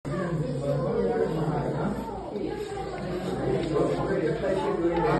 Use of rasp to smoothen the edges of the hoof.mp4